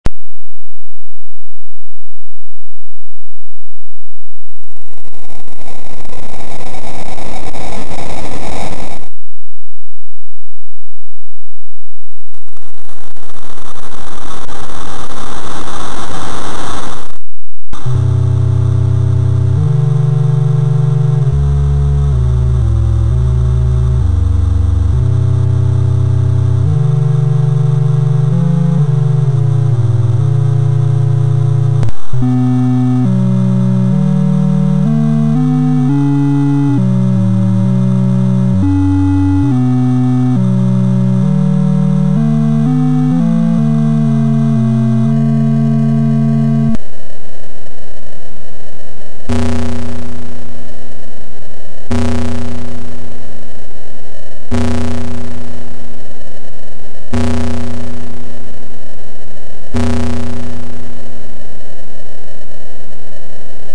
Opening Theme Song (with Gongs)